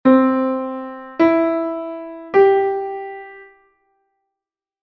Como o noso instrumento, a voz, é monódico (só podemos facer un son á vez), os acordes os interpretaremos en arpexio e así poderemos practicar a súa sonoridade.
Acorde Perfecto Maior
DO-MI-SOL